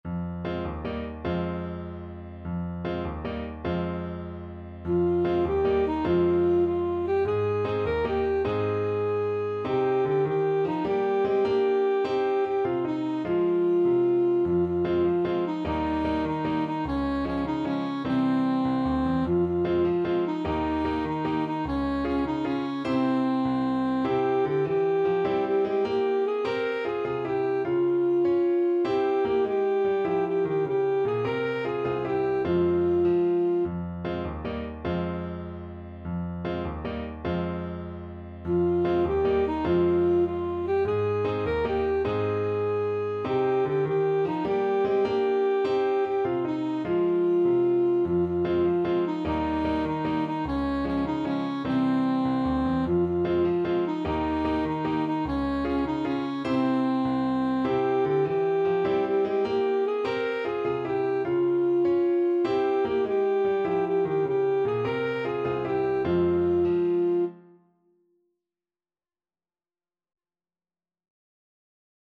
Alto Saxophone
Traditional Music of unknown author.
With energy .=c.100
6/8 (View more 6/8 Music)
C5-Bb5
Classical (View more Classical Saxophone Music)